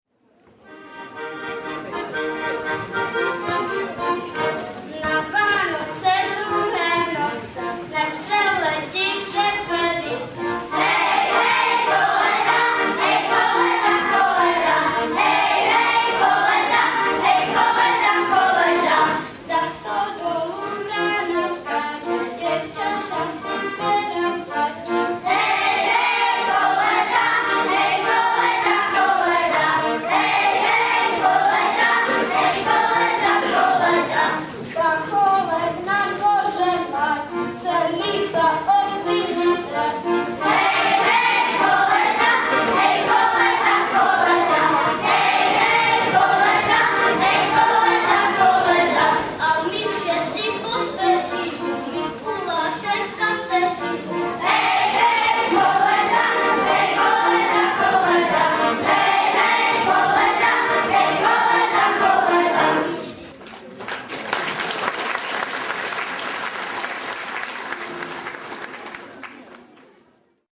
VÁNOČNÍ AKADEMIE ZŠ BÁNOV
Bánov … sál školy ... neděle 16.12.2007